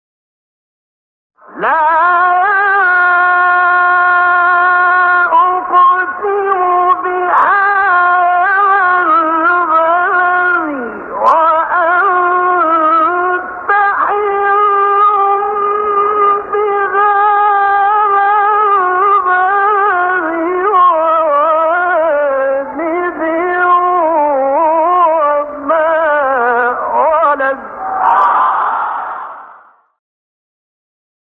گروه فعالیت‌های قرآنی: فرازهای صوتی دلنشین با صوت قاریان برجسته مصری ارائه می‌شود.
مقطعی از سوره مبارکه بلد باصدای شیخ راغب مصطفی غلوش